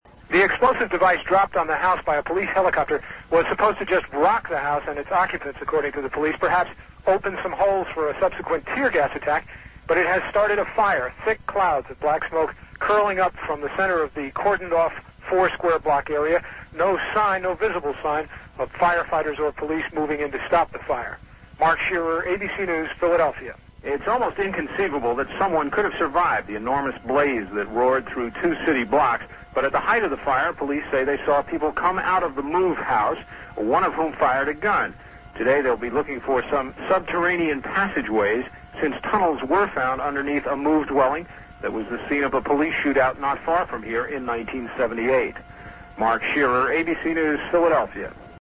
Police used a helicopter to drop two explosive devices on the rooftop, A fire resulted and 65 row houses were consumed. I was there for ABC Radio News: